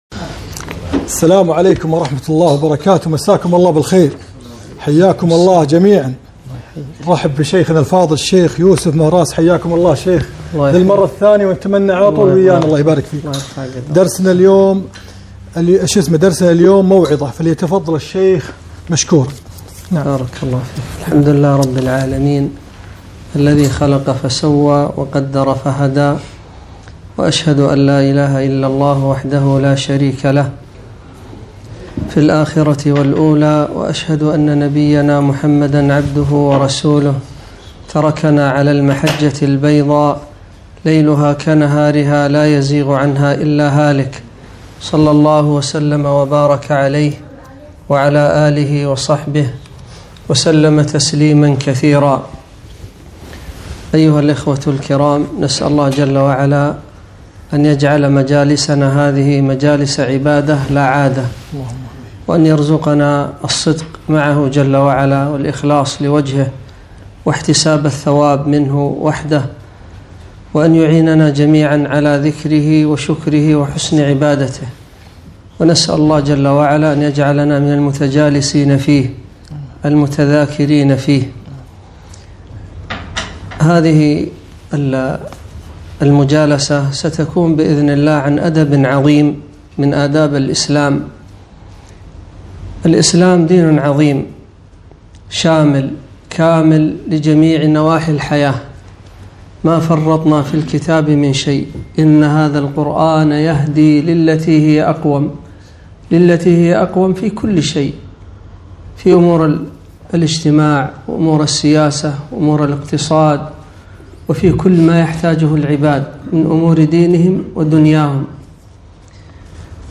كلمة - موعظة